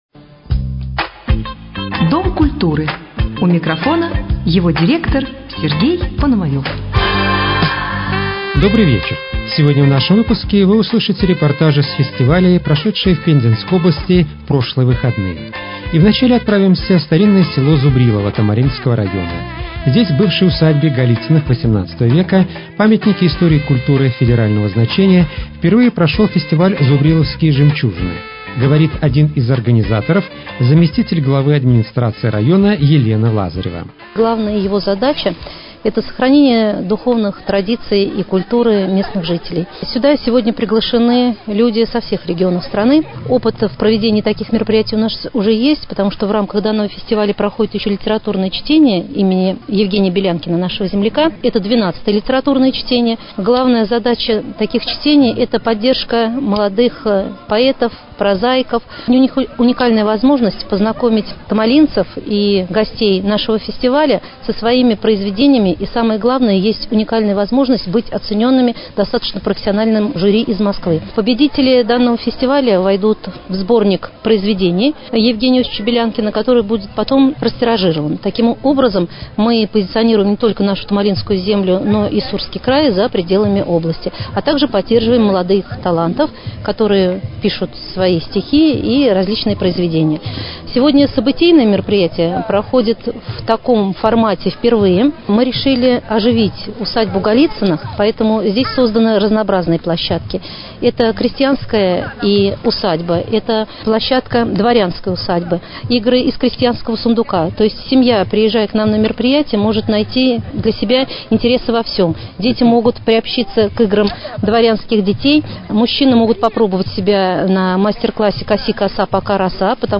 Репортажи: «Тихвинская ярмарка» и «Зубриловские жемчужины»